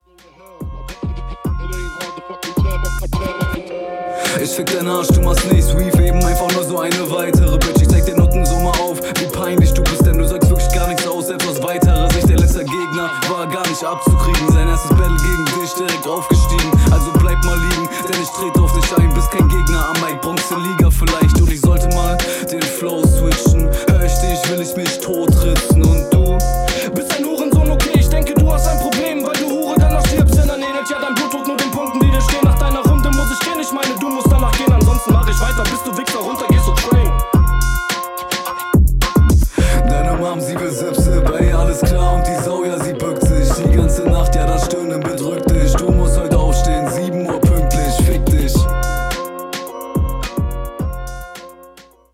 Jo Flow ist cool, gibt aber paar Stellen wo man fehlende Routine merkt.
Richtig cool gerappt, inhaltlich nicht so viel zu holen, geht halt viel über Attitude